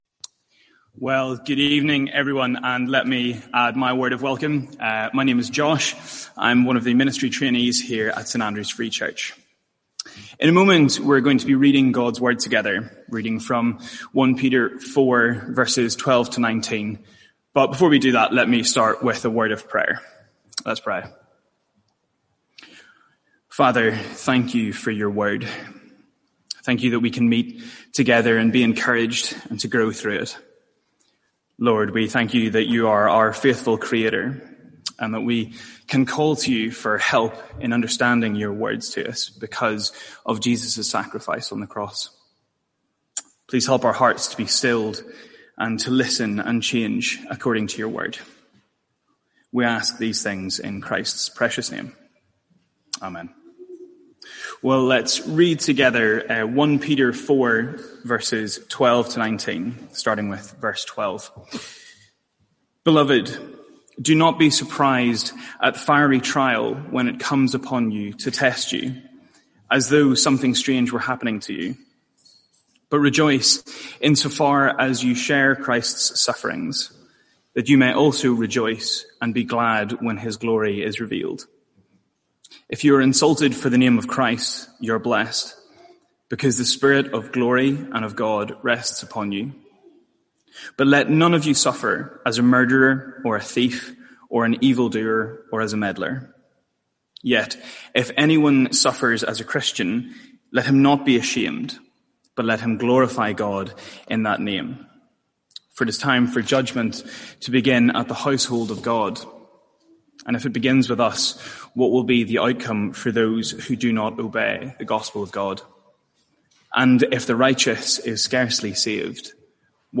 Sermons | St Andrews Free Church
From our evening series in 1 Peter.